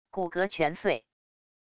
骨骼全碎wav音频